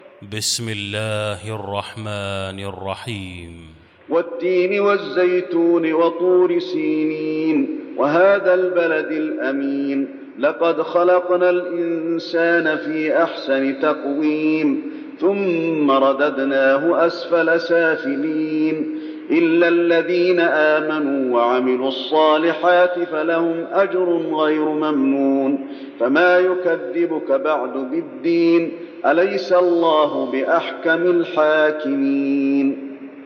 المكان: المسجد النبوي التين